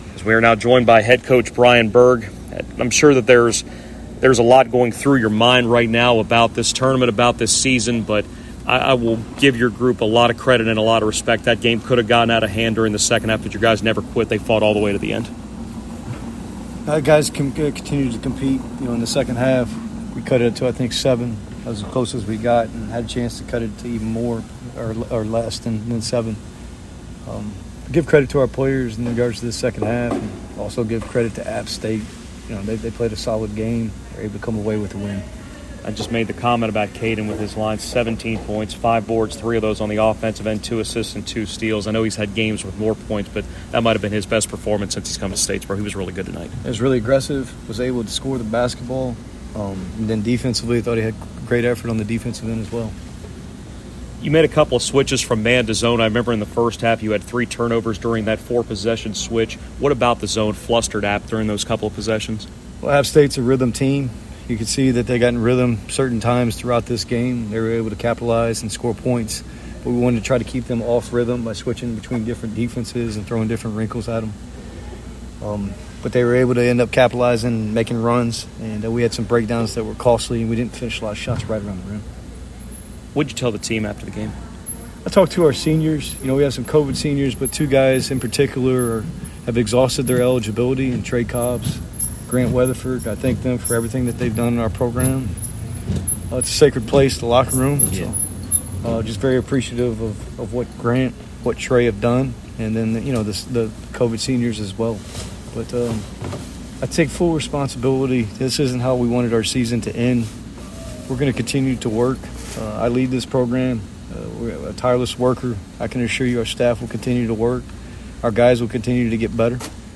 Postgame radio interview